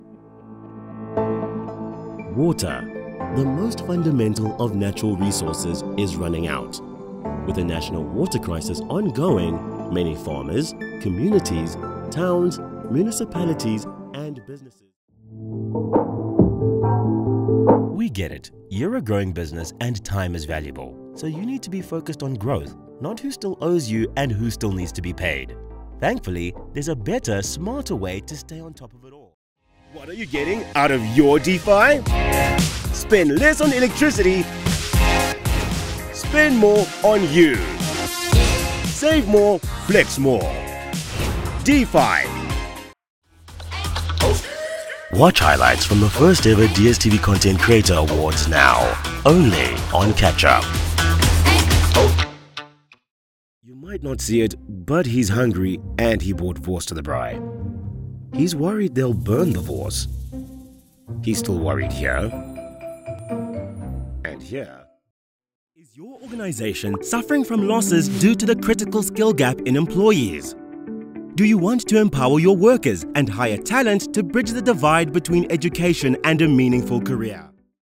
Inglés (sudafricano)
Conversacional
Corporativo
Natural